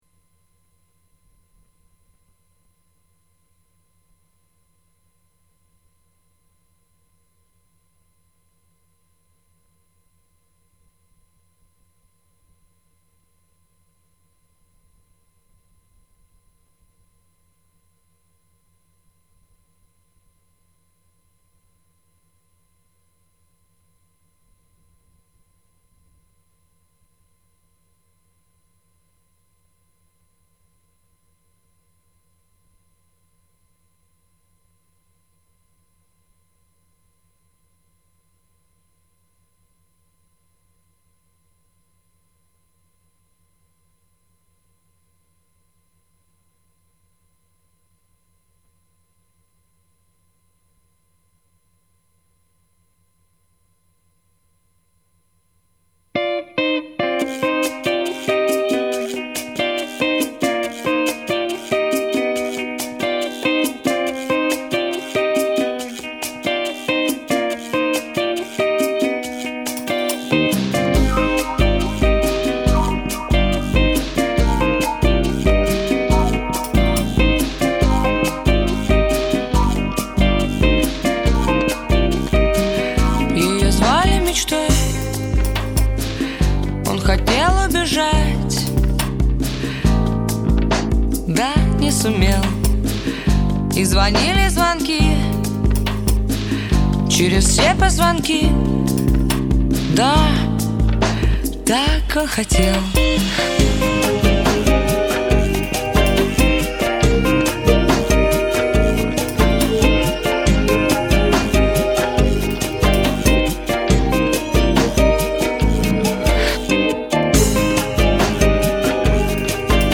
Русский рок Поп Рок